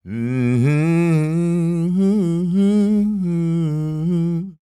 Blues Soul